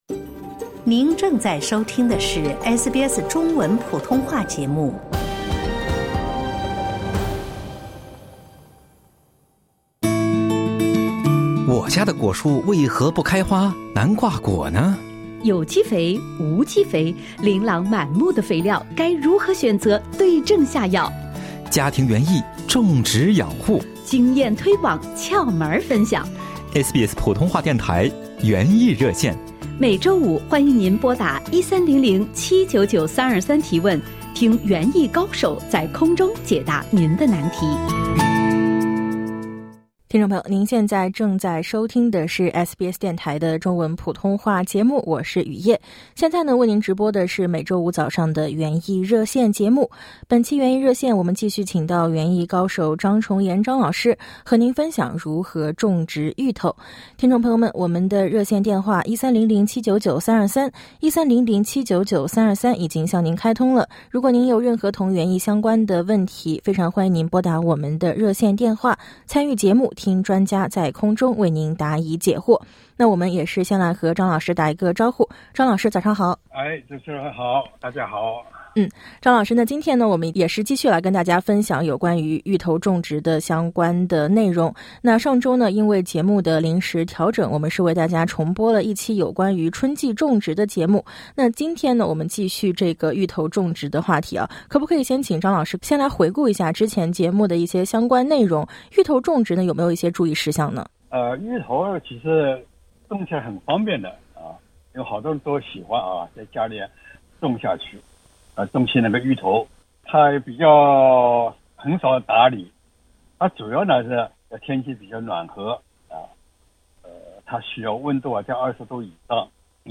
园艺热线节目每周五早上8点20分到9点在SBS普通话电台直播。